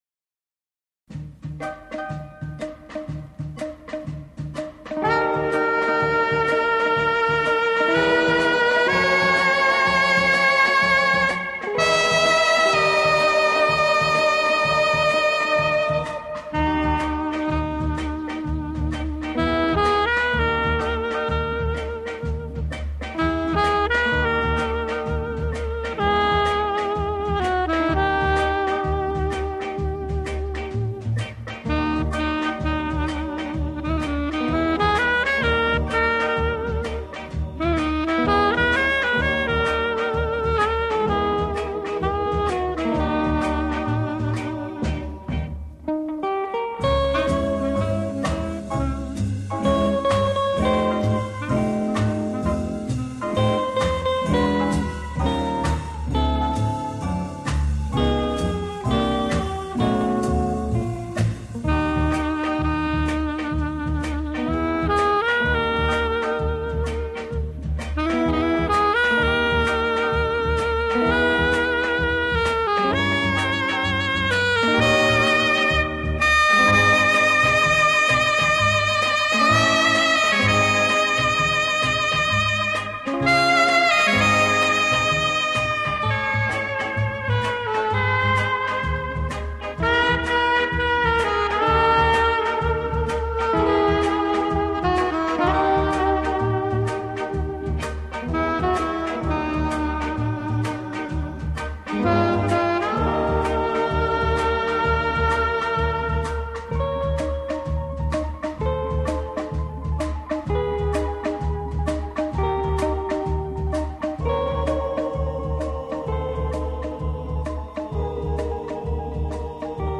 用萨克管演奏情调爵士乐，上世纪六十年代开始很走红，到上个世纪七十年